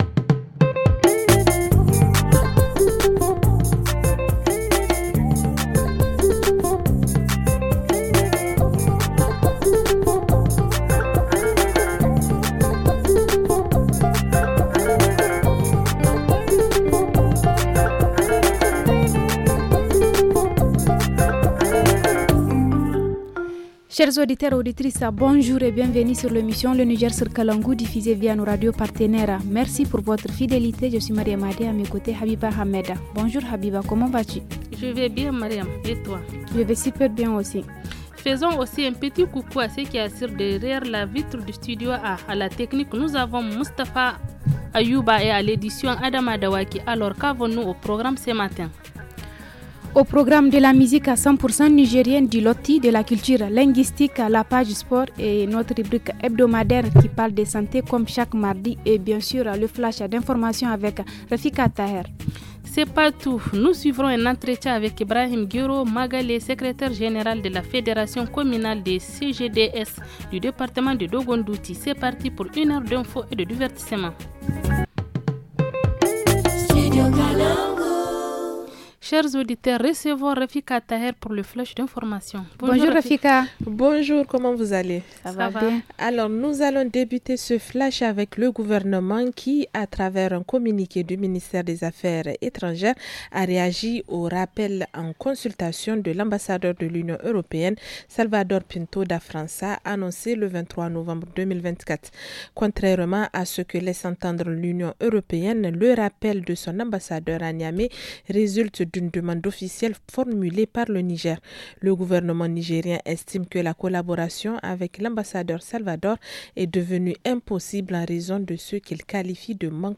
Dans la rubrique hebdomadaire, nous allons évoquer le cancer de la prostate chez les hommes. En reportage dans la région, explication sur la manière de réhabiliter nos villes face au changement climatique.